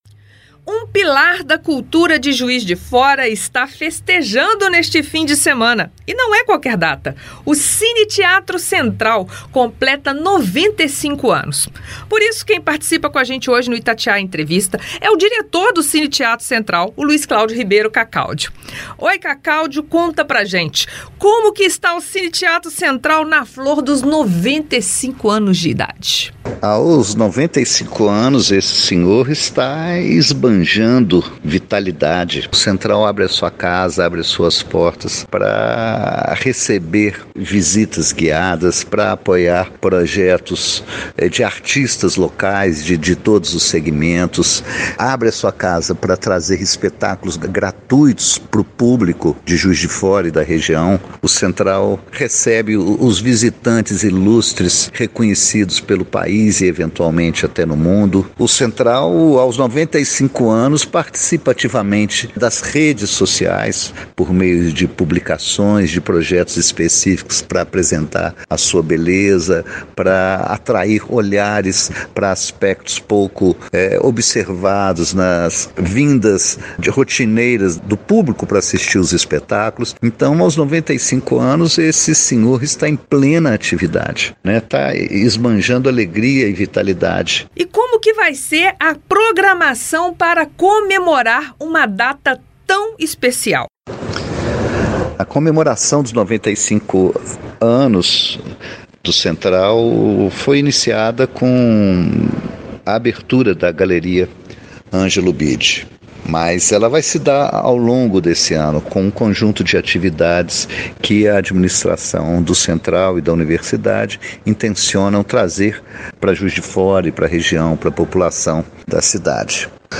ITATIAIA-ENTREVISTA-95-ANOS-CINE-THEATRO-CENTRAL.mp3